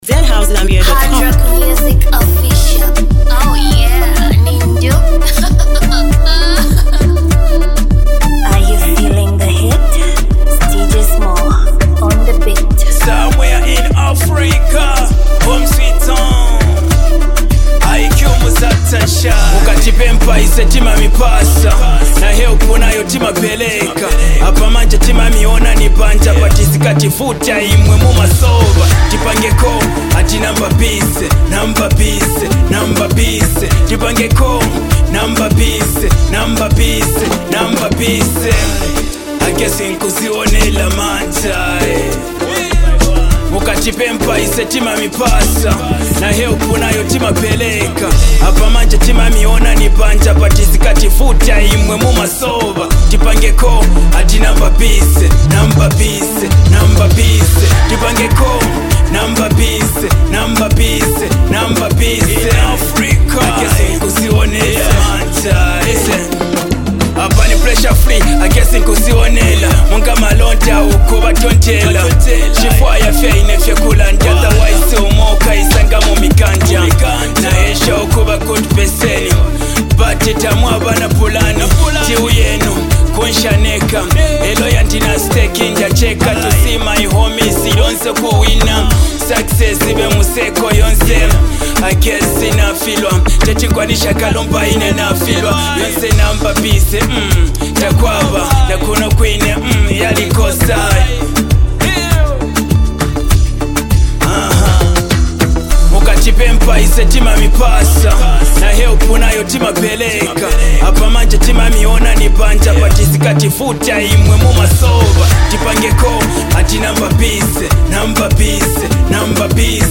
a high-energy anthem